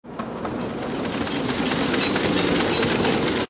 SZ_trolley_away.ogg